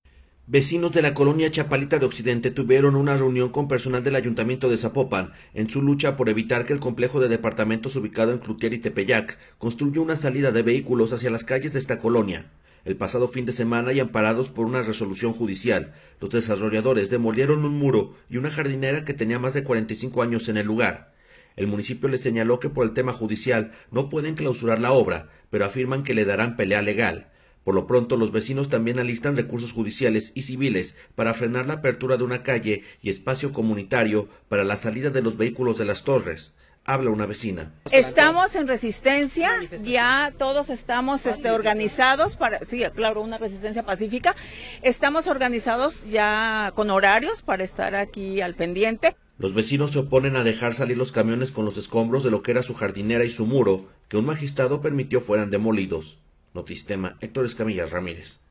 Habla una vecina.